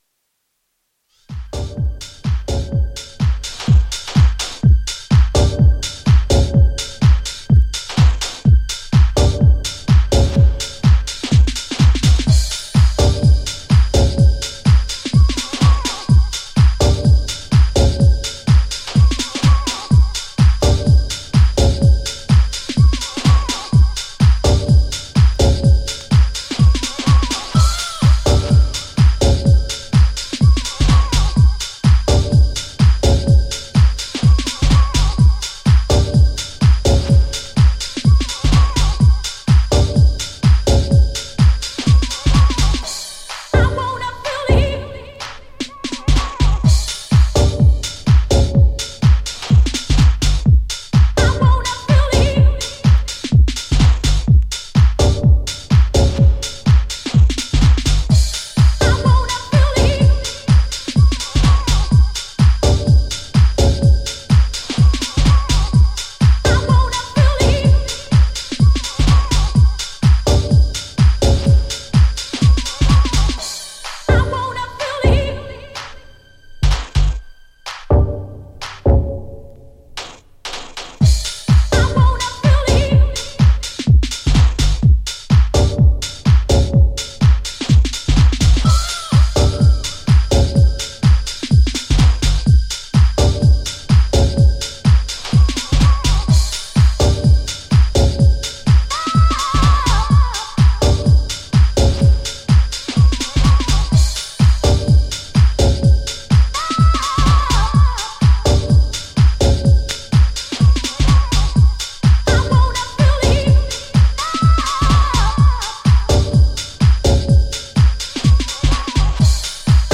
ジャンル(スタイル) DEEP HOUSE / FUNKY HOUSE